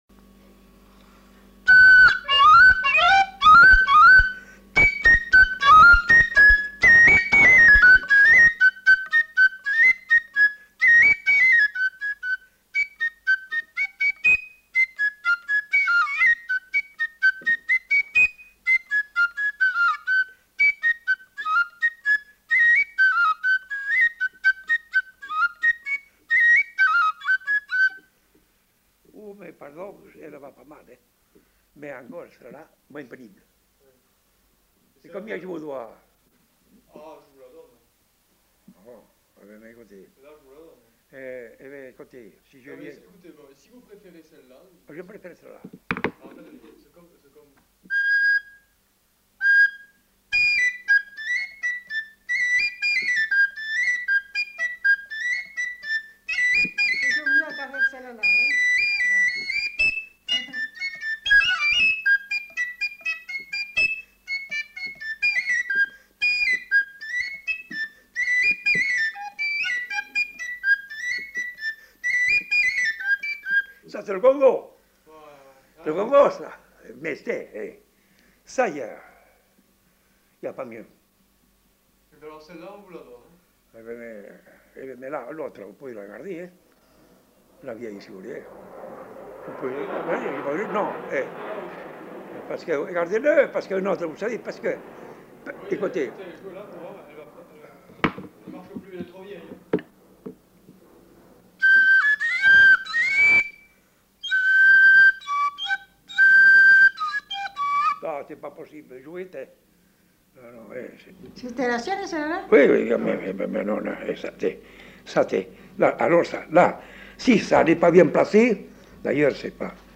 Aire culturelle : Bazadais
Département : Gironde
Genre : morceau instrumental
Instrument de musique : flûte à trois trous
Danse : congo